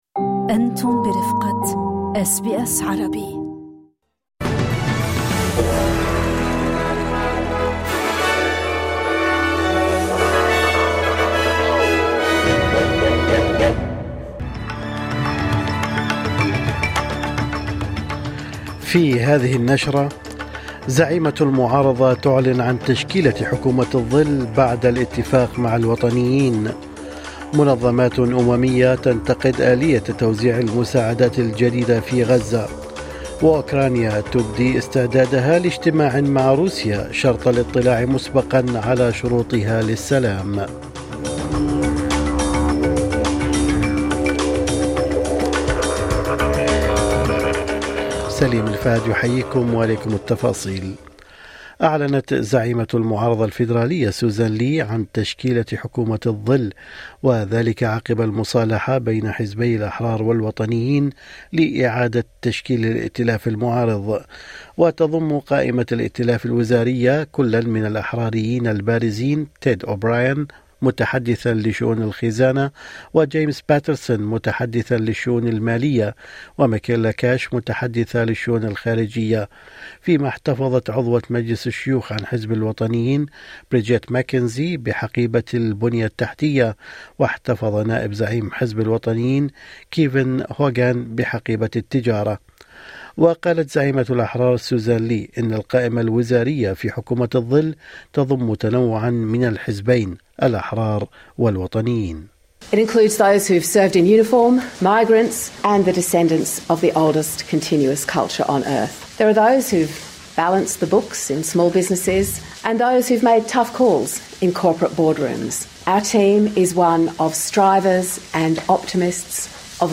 نشرة أخبار الصباح 29/5/2025